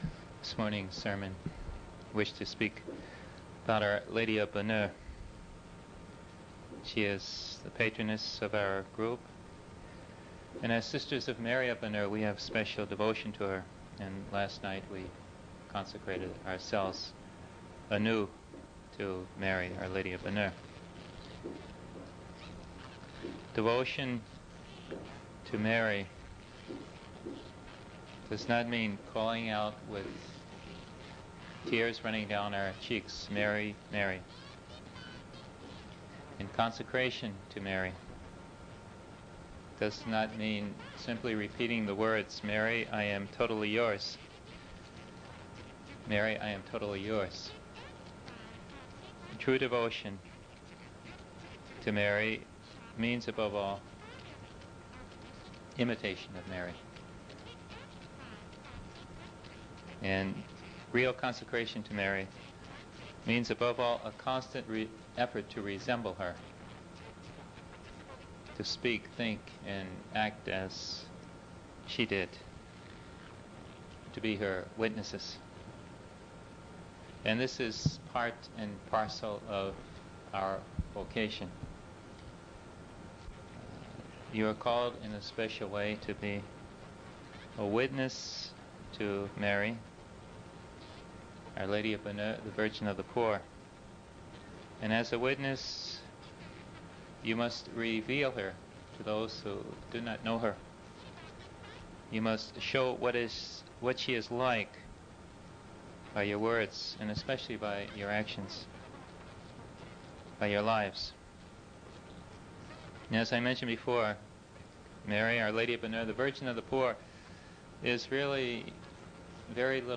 speaks to the Sisters of Mary about their vocation to be a witness of Our Lady of the Bannuex, the Virgin of the Poor.